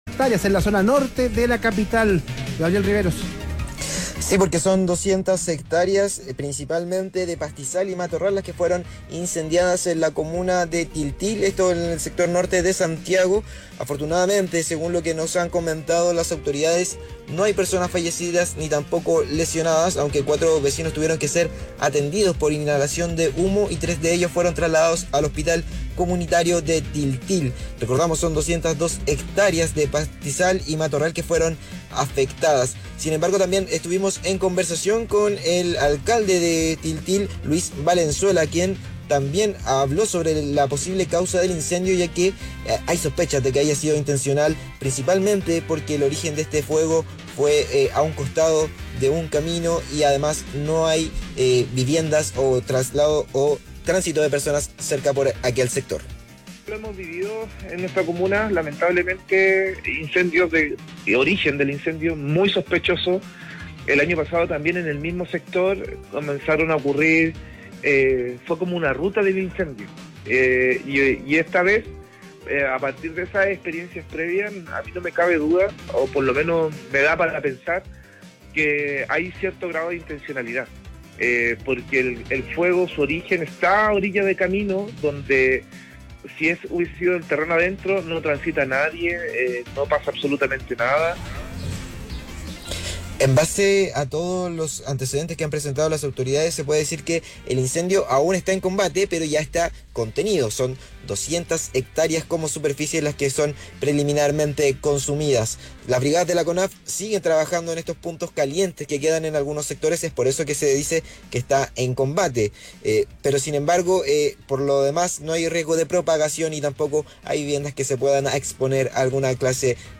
“No me cabe duda de que hay cierto grado de intencionalidad. El fuego, su origen, está a orilla de camino, no transita nadie, no pasa absolutamente nada”, afirmó el jefe comunal en conversación con Radio ADN.